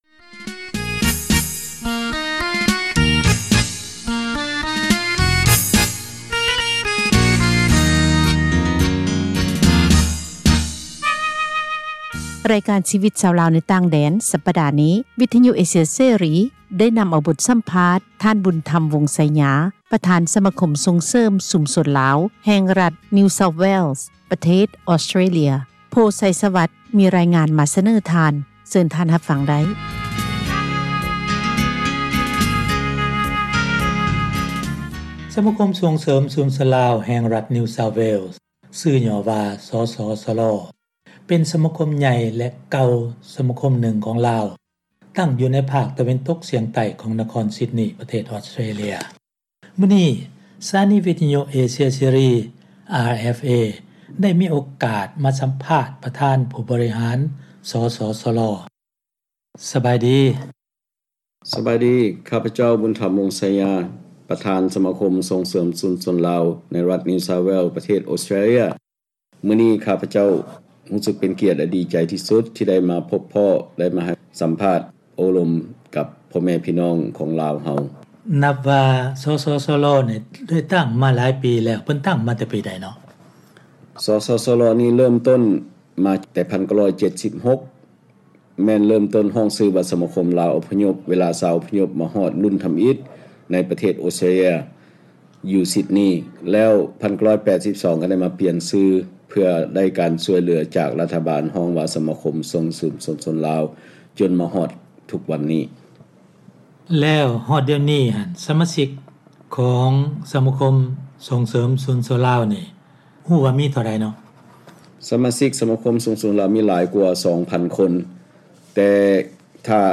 ມີສັມພາດ ມາສເນີທ່ານ.